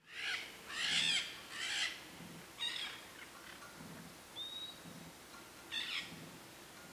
Tiriba-de-cara-suja (Pyrrhura molinae)
Nome em Inglês: Green-cheeked Parakeet
Fase da vida: Adulto
Localidade ou área protegida: Reserva Natural Privada Ecoportal de Piedra
Condição: Selvagem
Certeza: Gravado Vocal